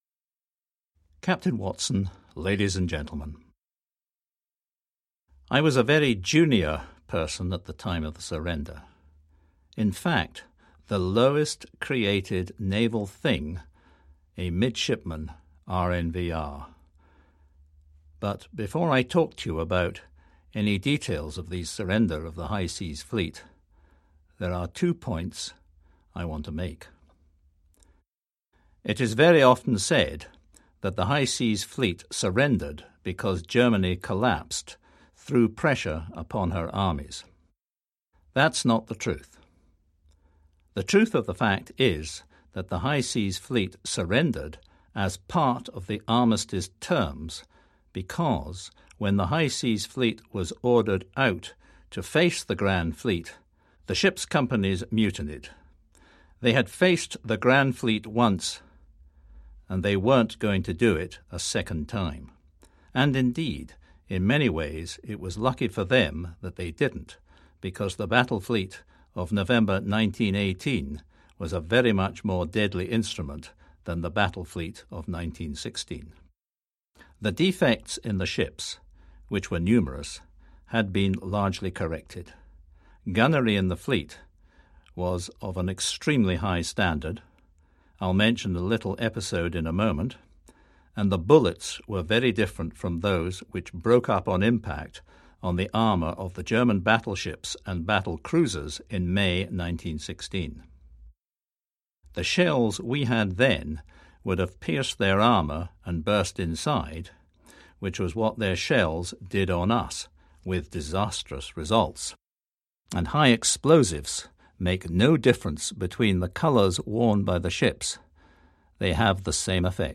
This audio recording was made from a transcript of Lord Cameron’s original address.